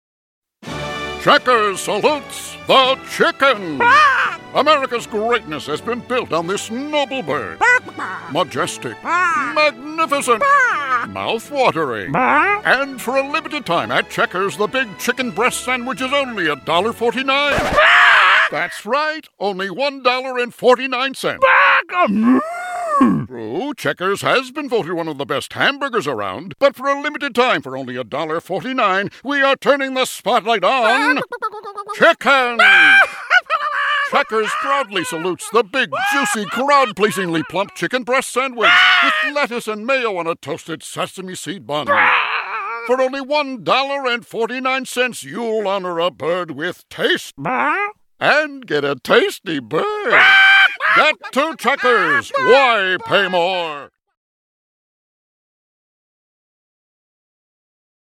Strategic Radio Commercial Production from Tampa, FL
We audition and cast West Coast voices because LA has the deepest talent pool in the country.